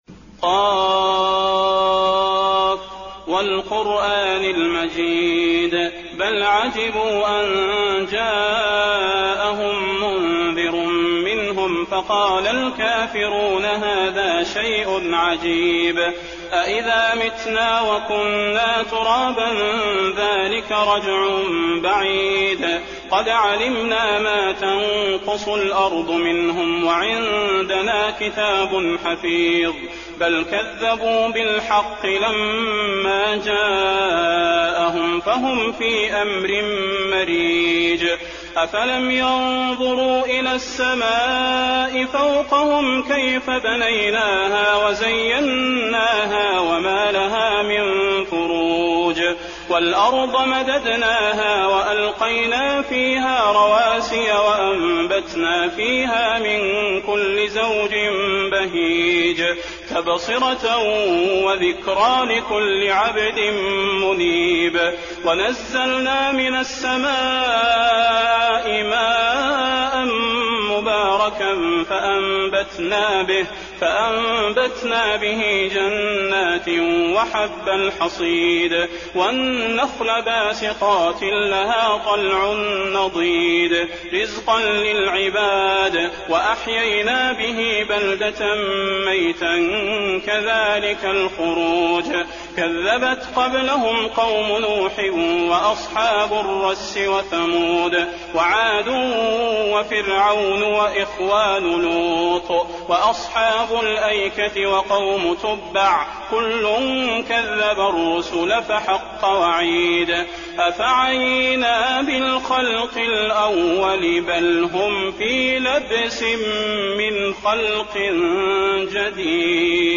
المكان: المسجد النبوي ق